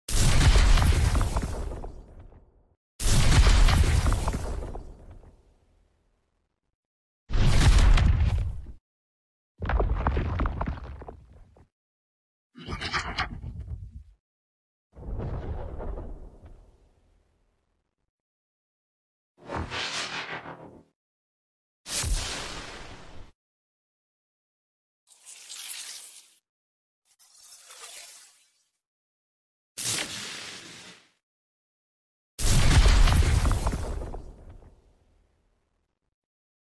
Showing what sounds I made sound effects free download
Showing what sounds I made and used for the redesign.